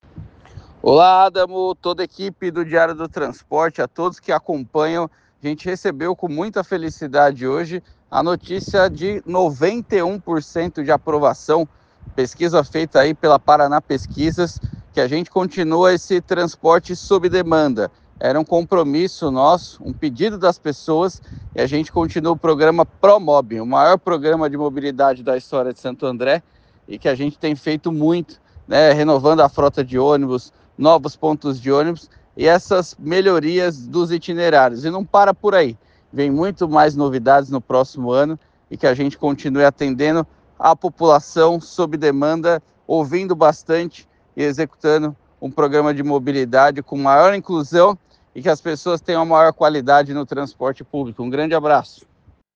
FALA DO PREFEITO